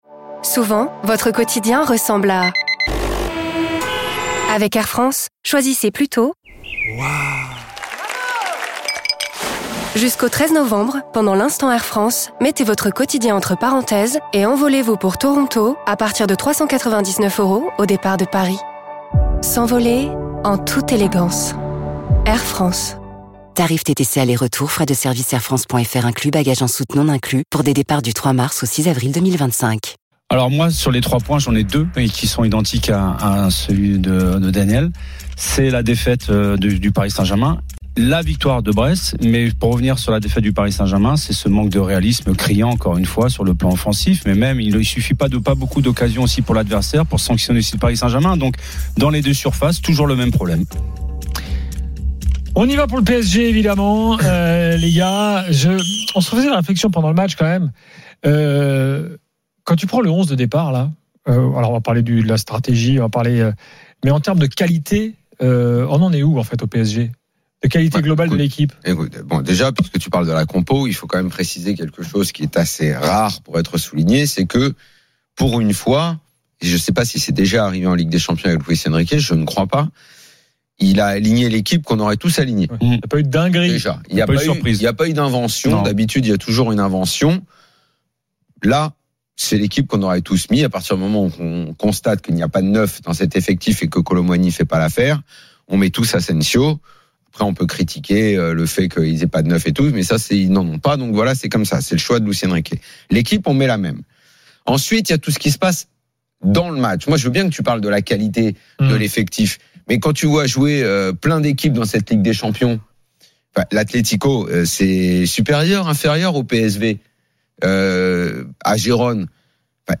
L’After foot, c’est LE show d’après-match et surtout la référence des fans de football depuis 19 ans !
avec les réactions des joueurs et entraîneurs, les conférences de presse d’après-match et les débats animés entre supporters, experts de l’After et auditeurs RMC.